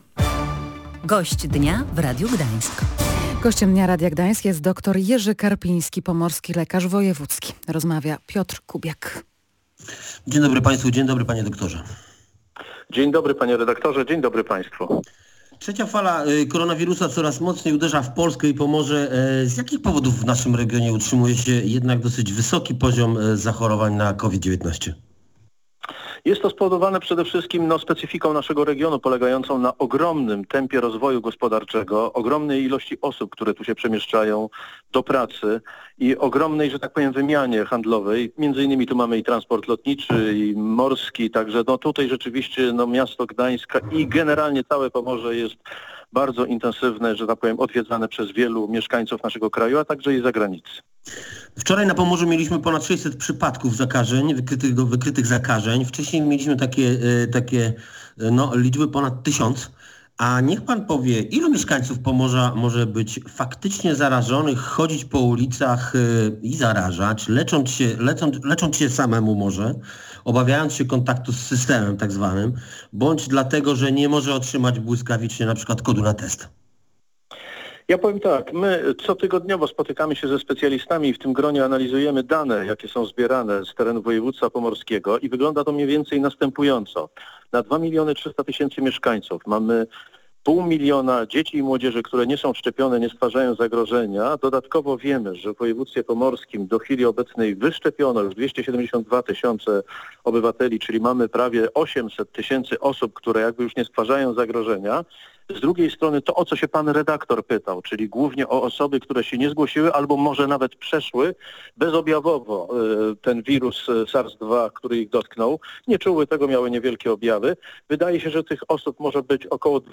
rozmawiał w audycji „Gość Dnia Radia Gdańsk” z doktorem Jerzym Karpińskim, pomorskim lekarzem wojewódzkim